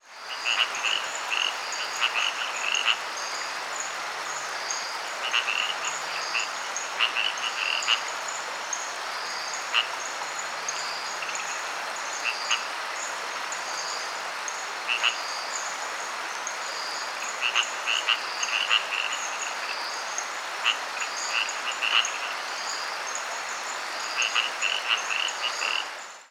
These frogs were recorded calling from rocks alongside a stream in a premontane forest.
The call of this species is a coarse quacking sound.
Here's a small chorus of Pug-nosed Treefrogs from along the same stream -
Pug-nosed Treefrog chrous from Anton Valley, Panama